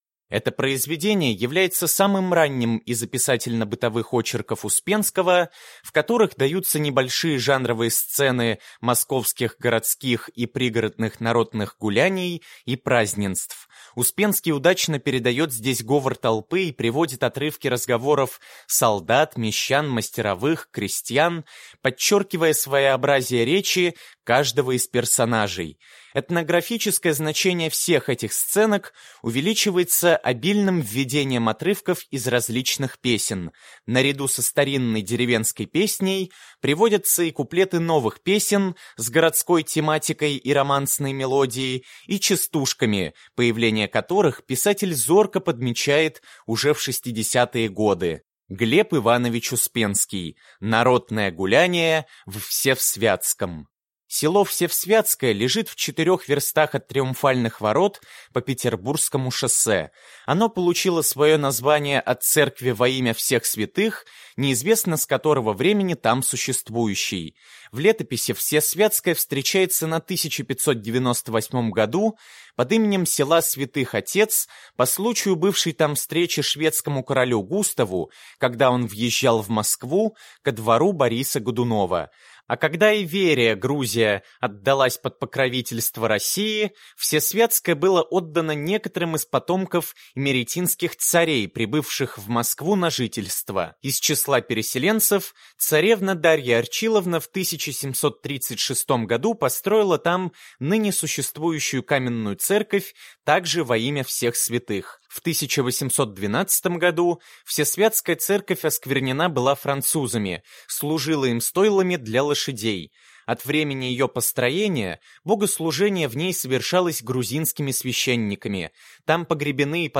Аудиокнига Народное гулянье в Всесвятском | Библиотека аудиокниг